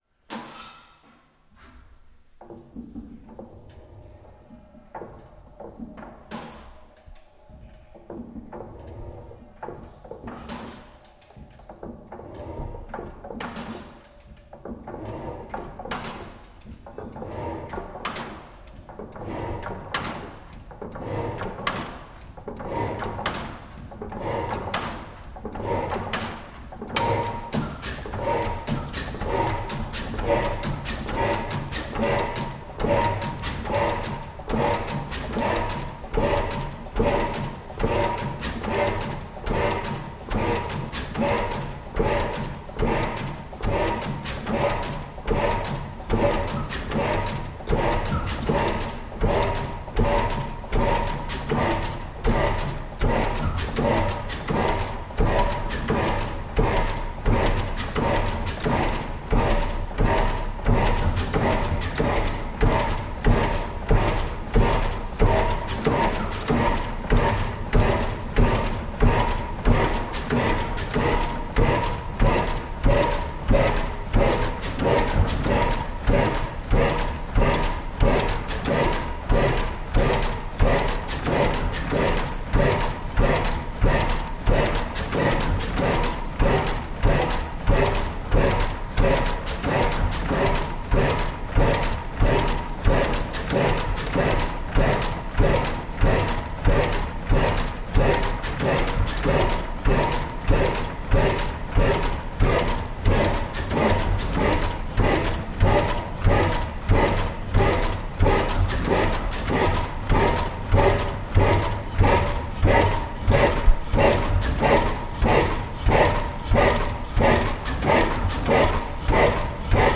Cambridge Museum of Techology - GAS ENGINE SEQUENCE